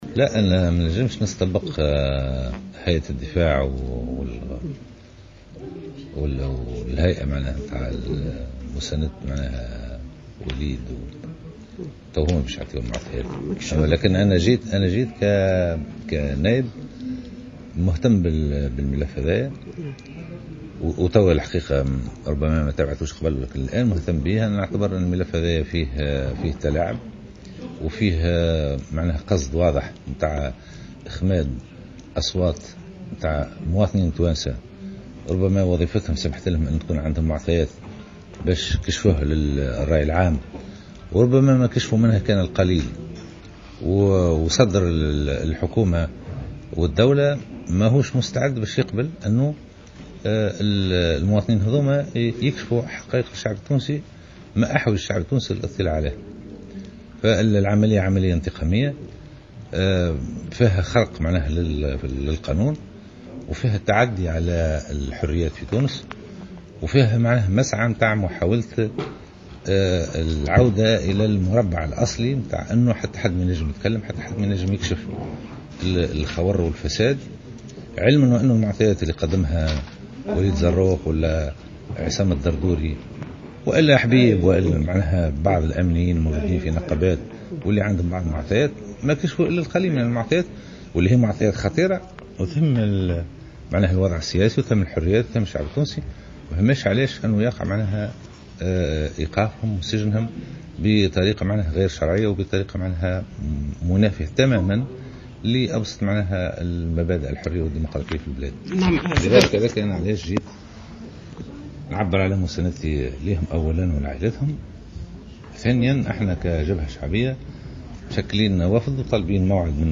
في تصريح للجوهرة أف أم، على هامش انعقاد ندوة صحفية